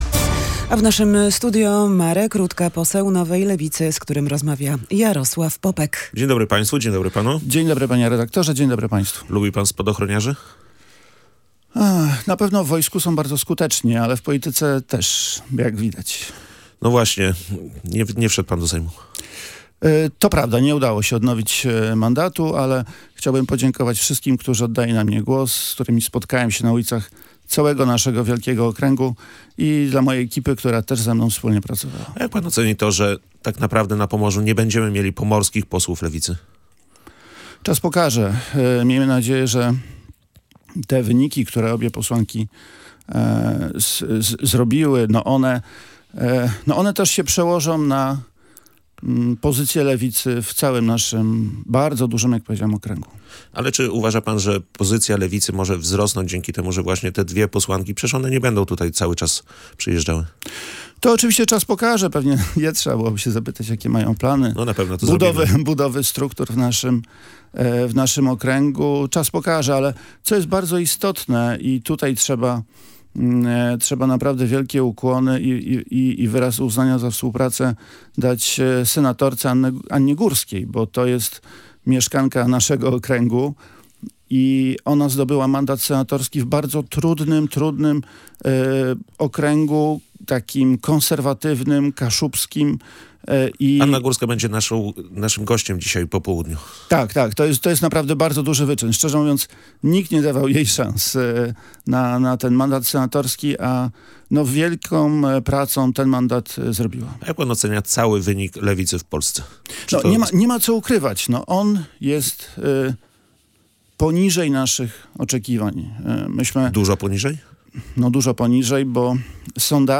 Wynik poniżej oczekiwań i wielki sukces Anny Górskiej – o tym w rozmowie